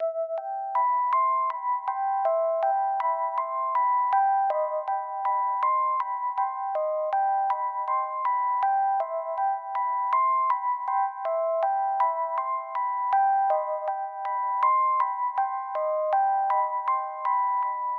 In music an ostinato is a rhythm or melody that is repeated throughout the composition.
Wurlitzer Ostinato
Wurlitzer-Ostinato.mp3